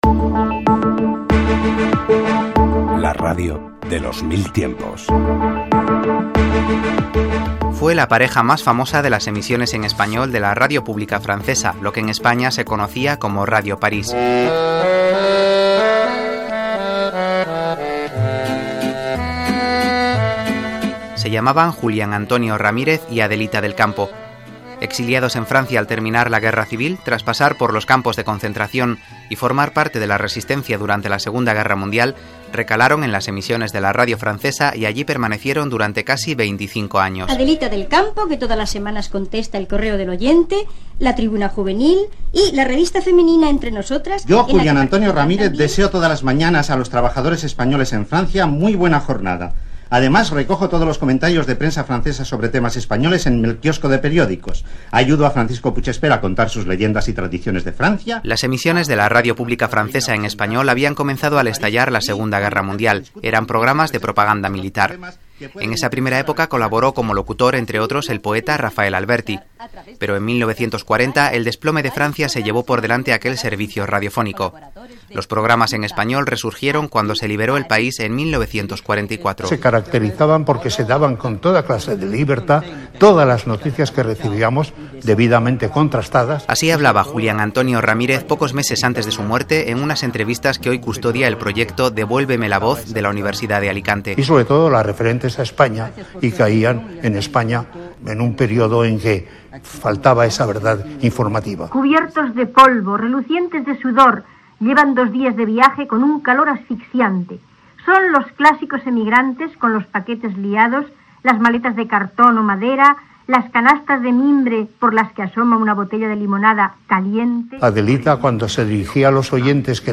Careta del programa i espai dedicat als programes en castellà de Radio París i a les figures d'Adelita del Campo i Julián Antonio Ramírez
Divulgació